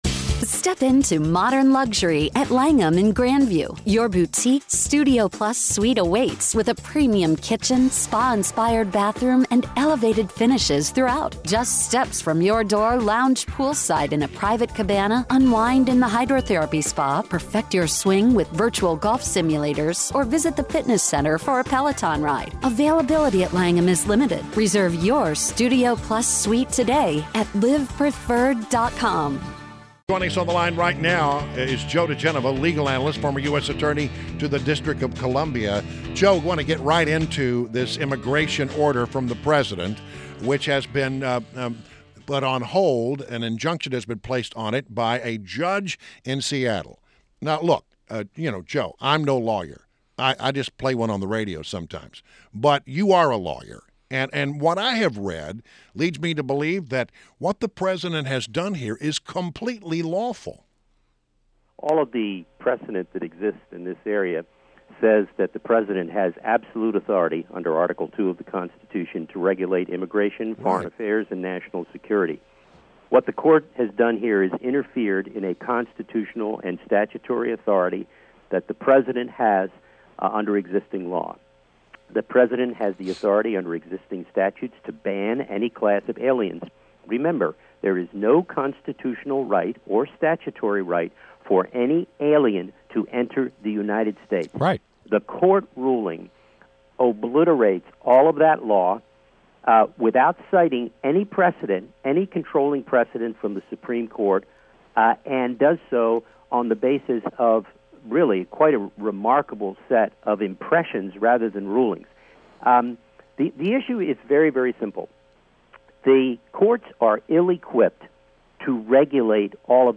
WMAL Interview - JOE DIGENOVA - 02.06.17
INTERVIEW – JOE DIGENOVA – legal analyst and former U.S. Attorney to the District of Columbia